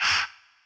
Vox
Haa.wav